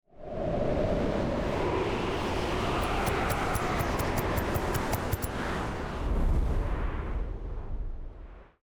SFX_Schlappentornado_03.wav